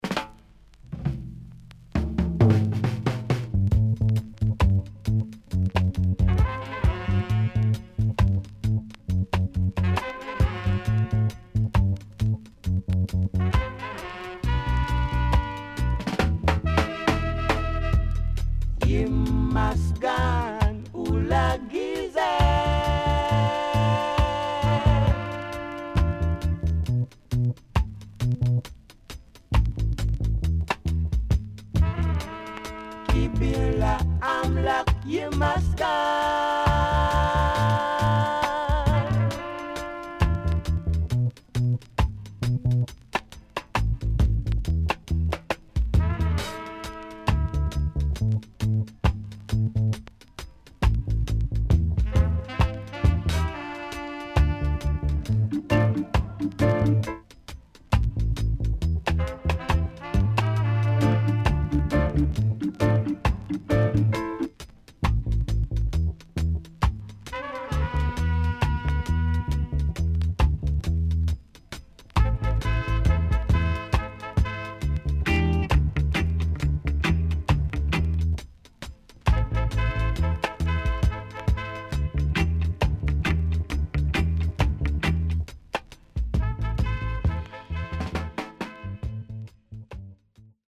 CONDITION SIDE A:VG(OK)〜VG+
Great Roots Vocal
SIDE A:うすいこまかい傷ありますがノイズあまり目立ちません。